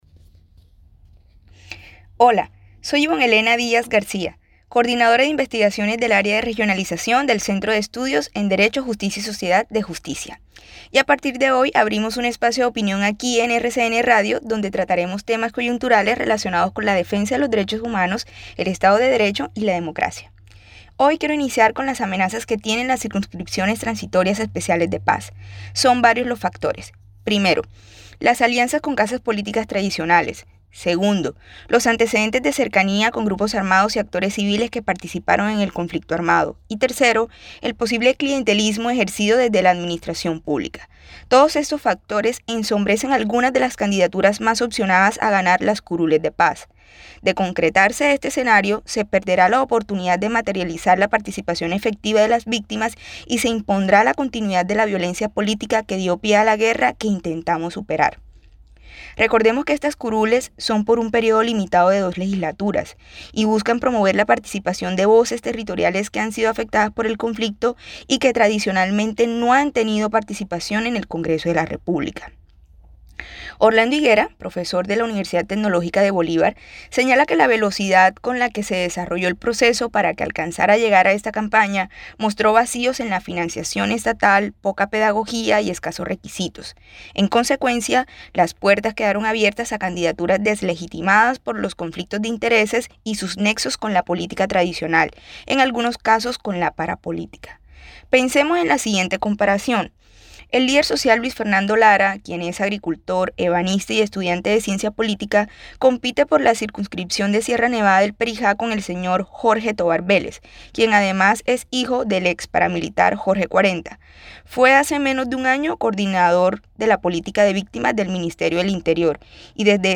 Abrimos un nuevo espacio de opinión en RCN Radio Cartagena.
Las-amenazas-a-curules-de-paz-Dejusticia-en-RCN-Cartagena.mp3